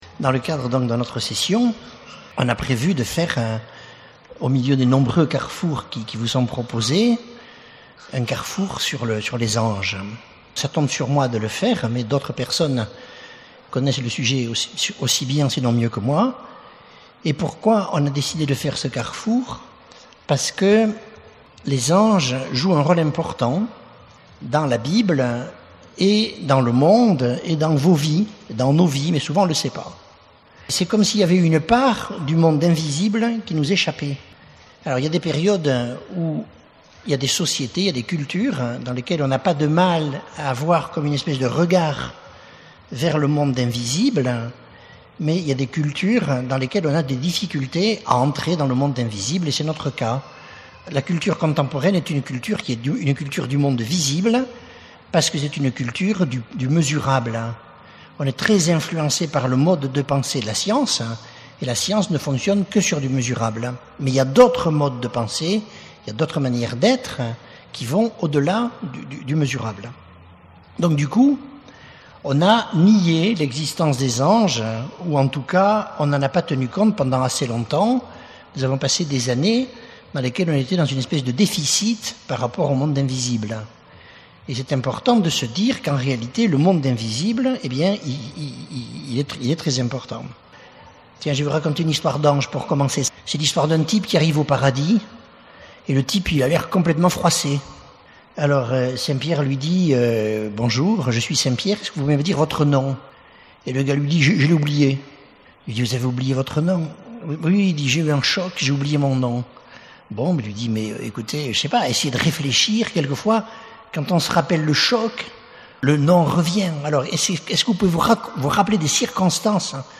Enseignement
Session famille 3 (du 4 au 9 août 2012)